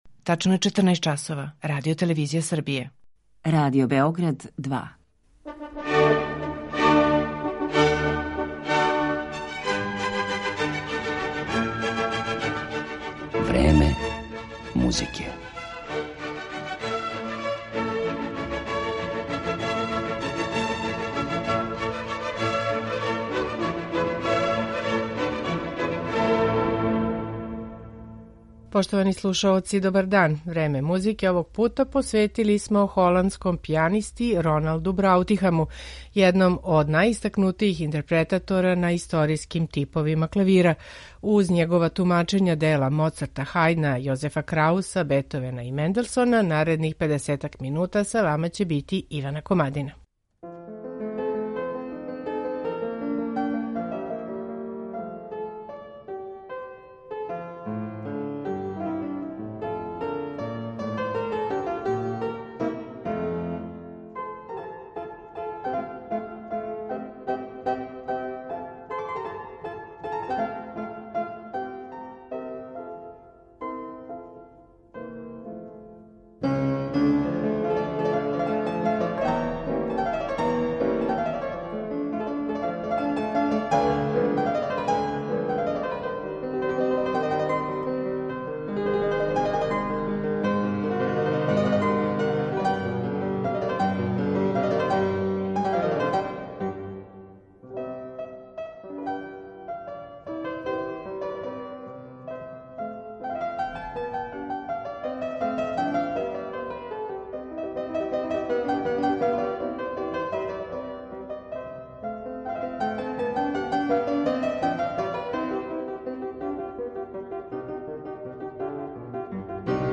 Срећна је околност што се Браутихам определио за изванредне инструменте које израђује Пол МекНалти и тако постао један од најзаслужнијих музичара који су тзв. „фортепиано" извукли из сенке. У данашњем Времену музике Роналда Браутихама представићемо интерпретацијама дела Моцарта, Хајдна, Јозефа Крауса, Бетовена и Менделсона.